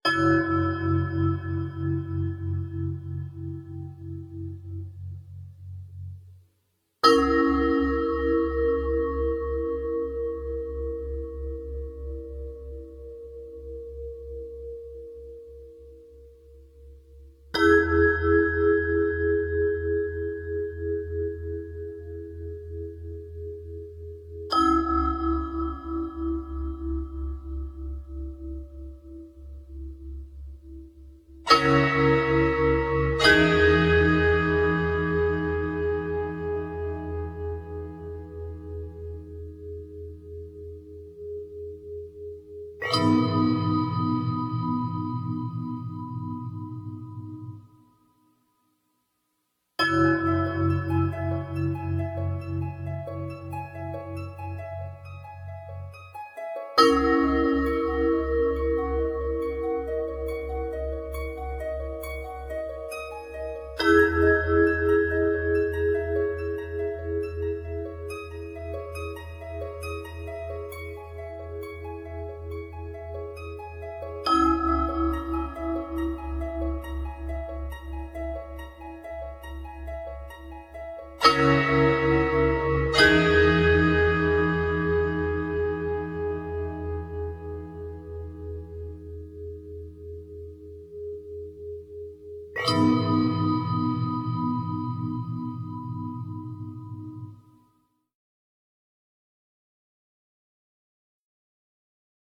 dark, lush and more than a little offbeat.